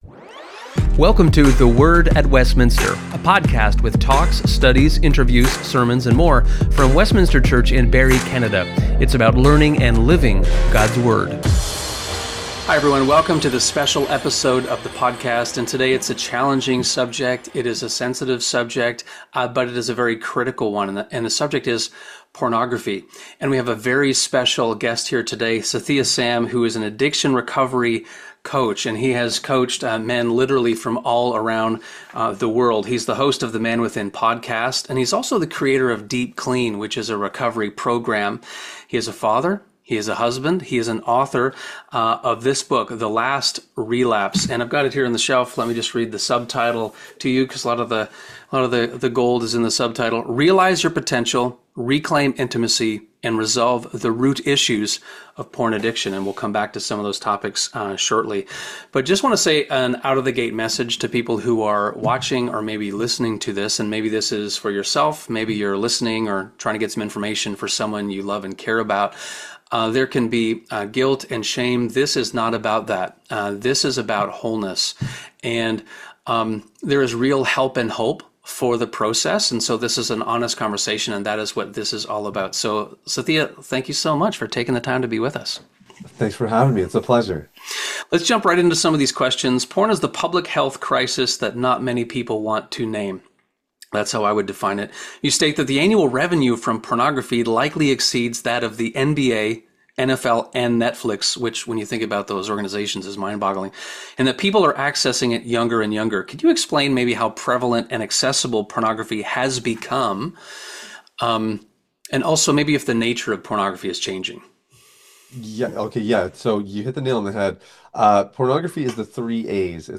This isn’t about shame or guilt. It’s a very honest conversation about real help and hope for those seeking wholeness.